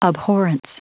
Prononciation du mot abhorrence en anglais (fichier audio)
Prononciation du mot : abhorrence